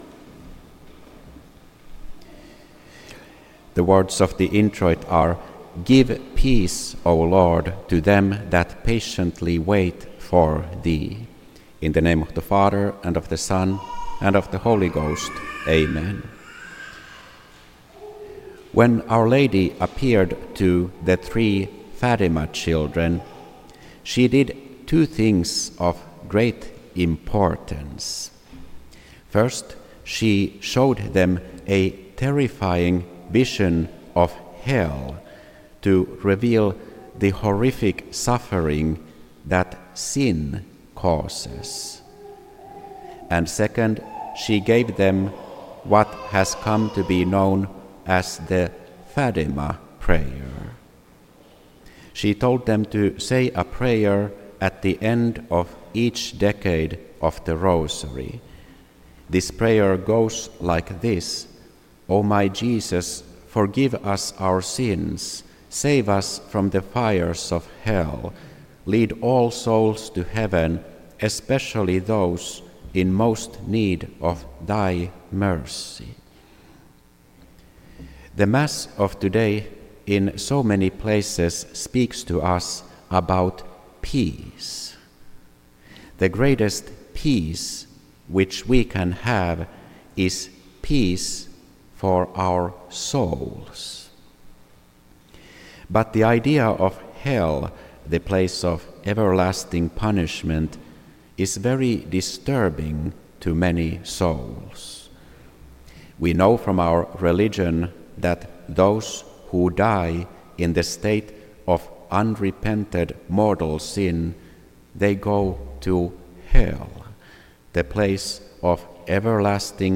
This entry was posted on Monday, October 13th, 2025 at 2:01 pm and is filed under Sermons.